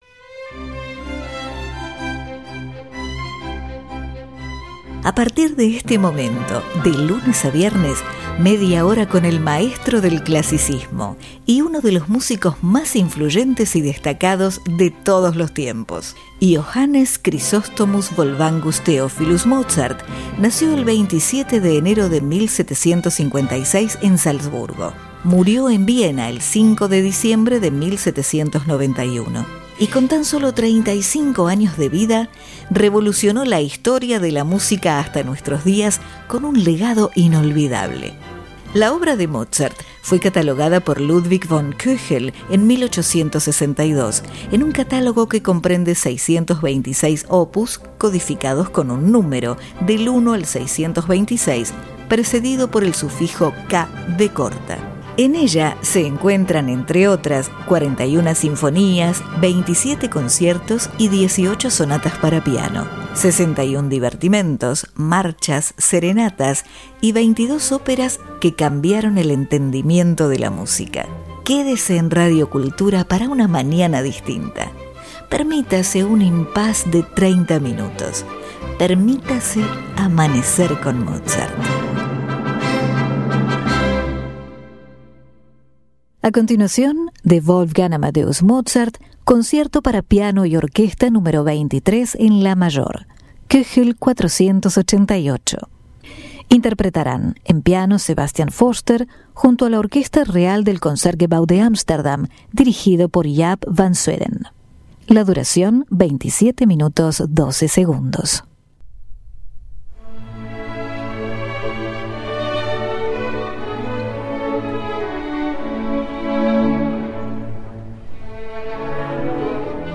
Concierto Para Piano Y Orquesta Nº 23 En La Mayor K. 488
Orquesta Real Del Concertgebouw De Amsterdam